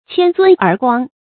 謙尊而光 注音： ㄑㄧㄢ ㄗㄨㄣ ㄦˊ ㄍㄨㄤ 讀音讀法： 意思解釋： 尊者謙虛而顯示其美德。